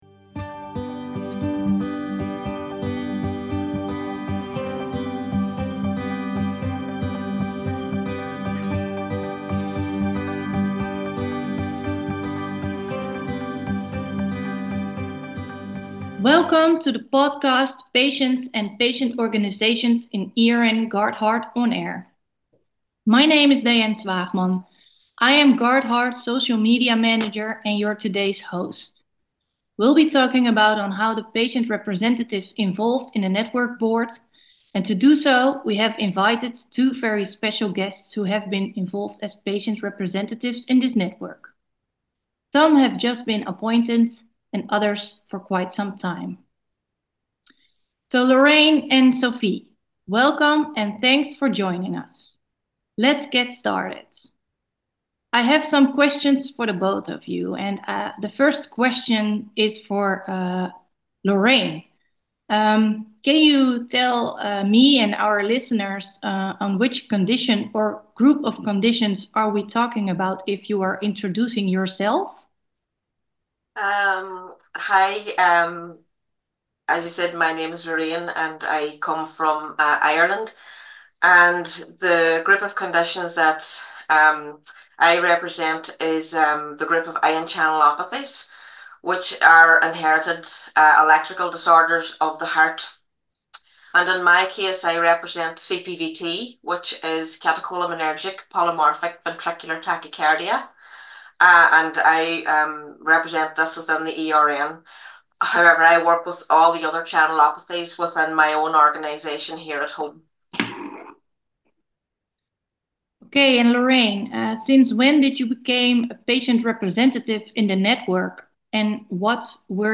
In March 2024 ERN GUARDHEART started with a podcast series in which all ePags or patient representatives are requested to participate. The idea is that in each recording, we are interviewing two representatives as a duo.